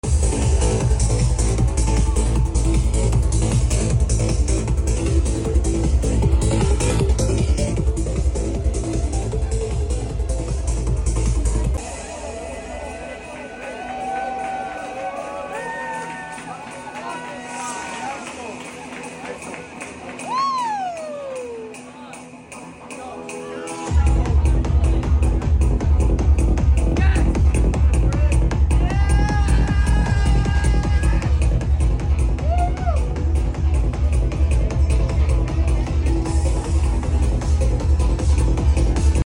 psytrance at 155bpm is something sound effects free download